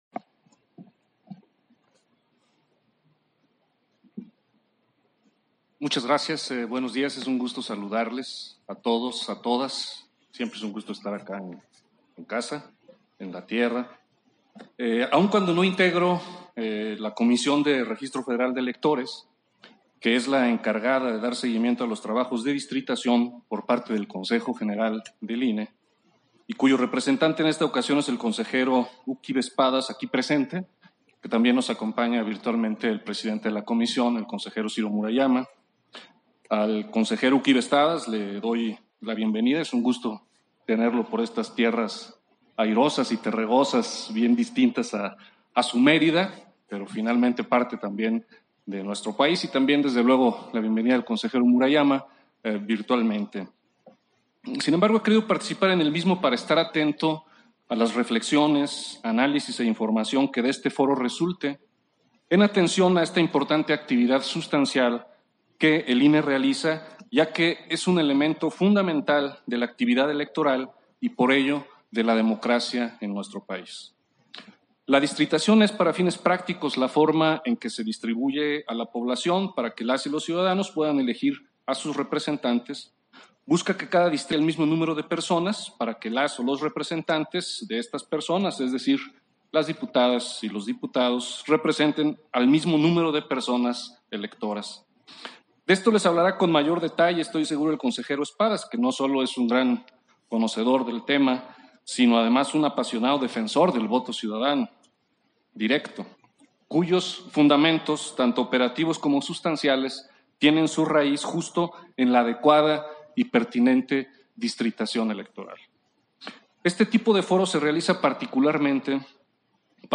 220322_AUDIO_INTERVENCIÓN-CONSEJERO-FAZ-FORO-ESTATAL-DE-DISTRITACIÓN-NACIONAL-ELECTORAL-2021-2023 - Central Electoral